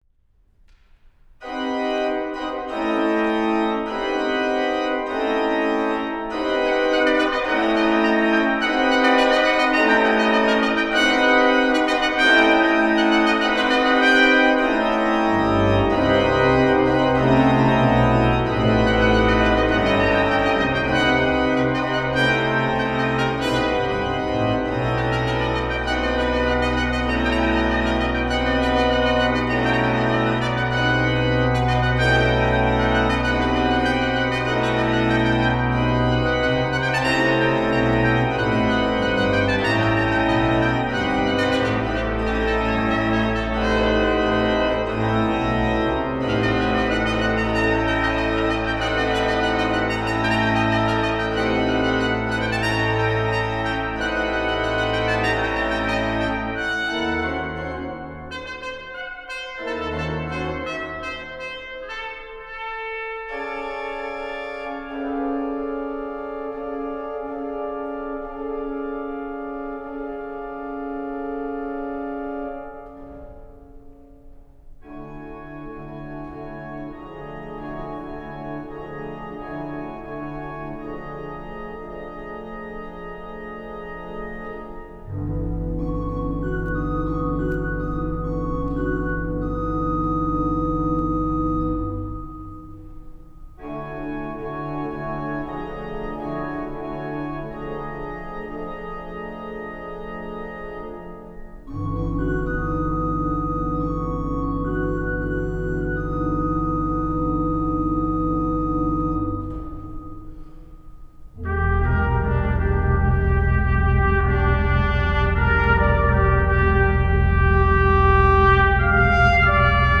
Recordings of select live performances
Lagerquist Hall
organ.